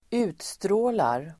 Uttal: [²'u:tstrå:lar]